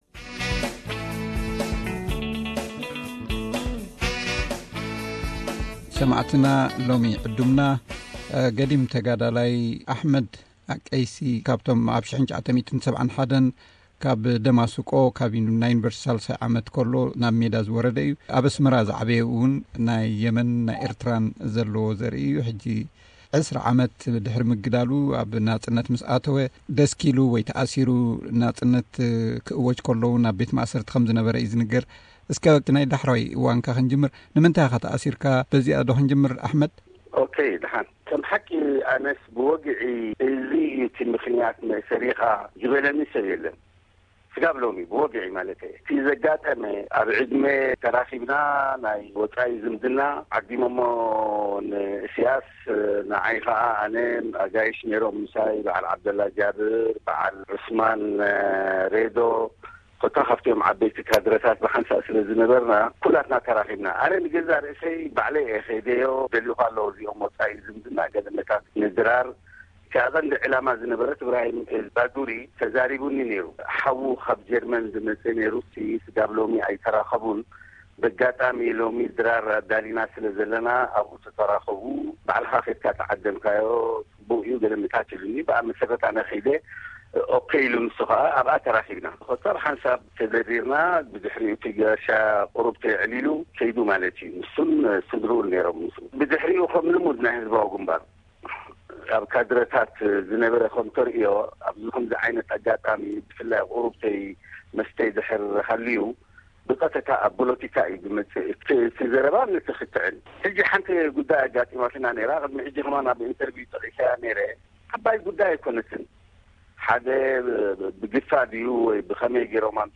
ቃለ መሕትት ምስ ተጋ